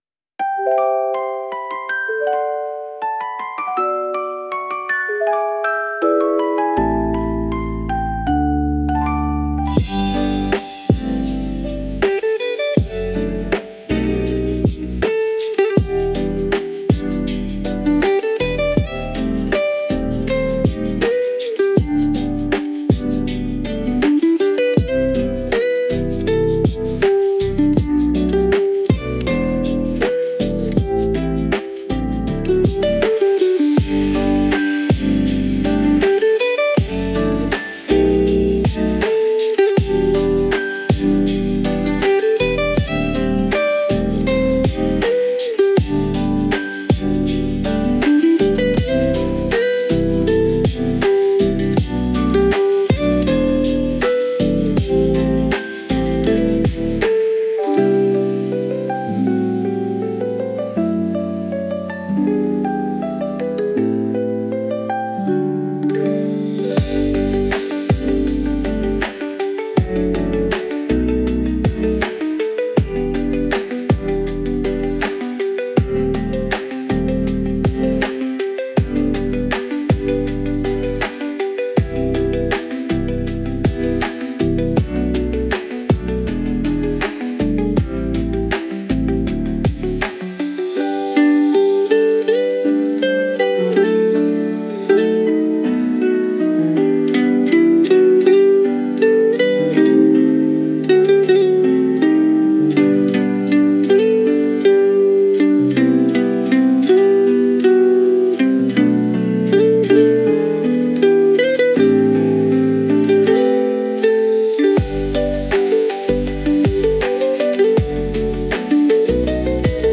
R&B バラード